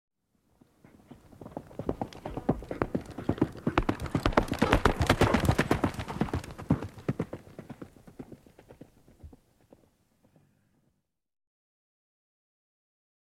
دانلود صدای اسب 19 از ساعد نیوز با لینک مستقیم و کیفیت بالا
جلوه های صوتی
برچسب: دانلود آهنگ های افکت صوتی انسان و موجودات زنده دانلود آلبوم انواع صدای شیهه اسب از افکت صوتی انسان و موجودات زنده